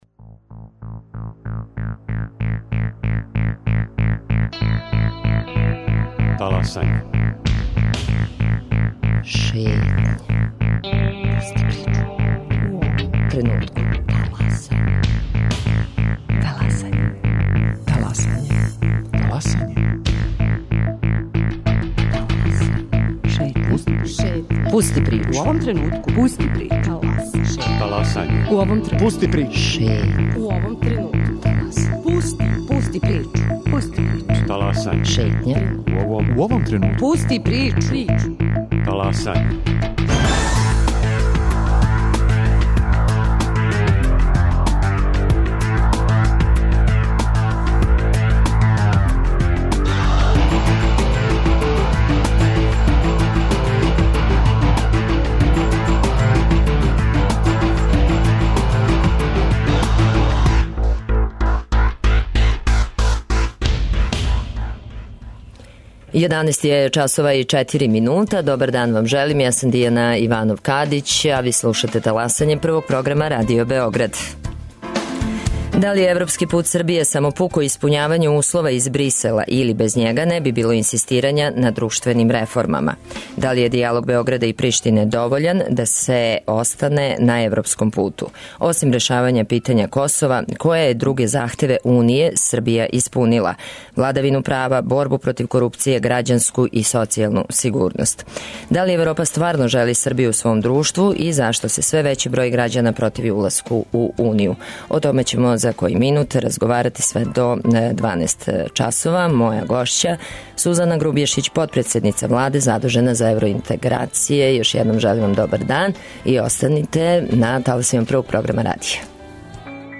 Гост Таласања је Сузана Грубјешић, потпредседница Владе задужена за евроинтеграције.